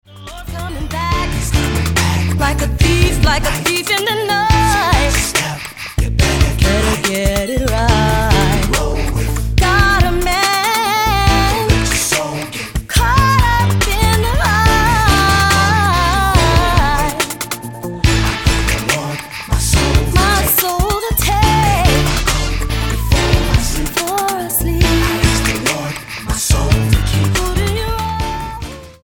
Style: Gospel Approach: Praise & Worship